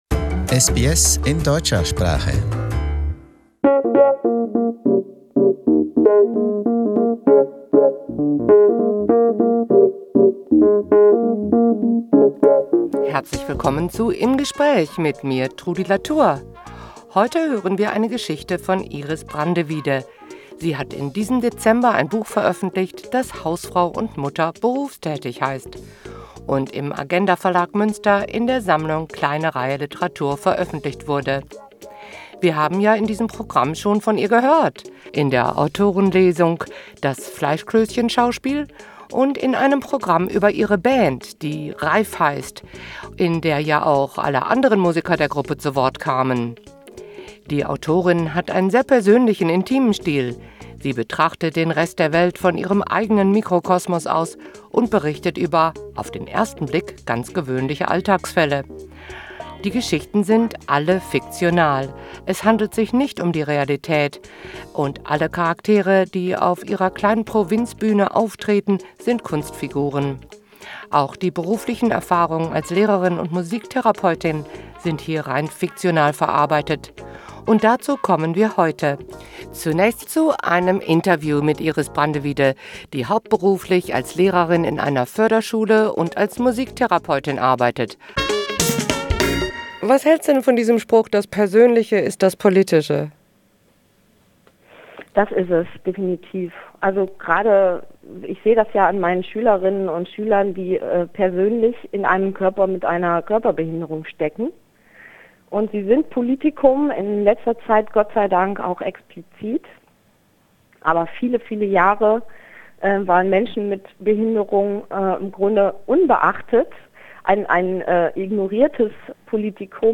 Im Gespräch
Im zweiten Teil des Programms gibt es dazu eine Geschichte, vorgelesen von der Autorin selbst.
Autorenlesung